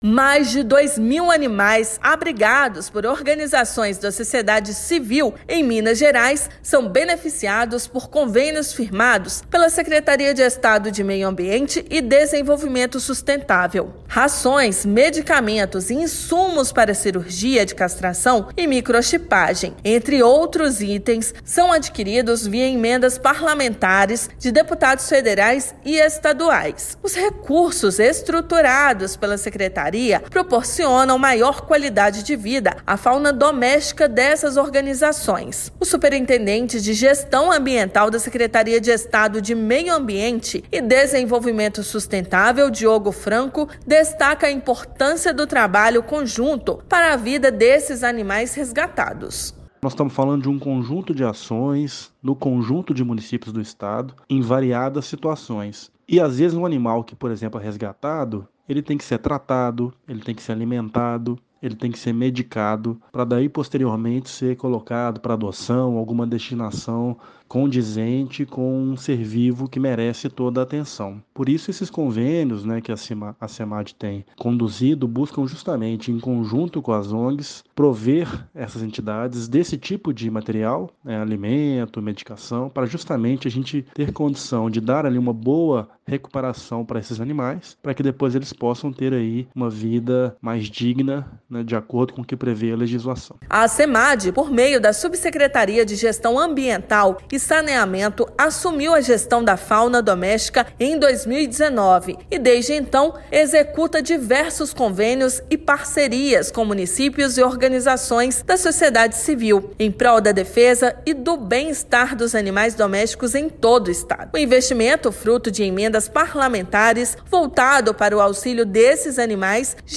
Rações, medicamentos e insumos para cirurgia de castração e microchipagem são destinados a organizações que cuidam da fauna em Minas. Ouça matéria de rádio.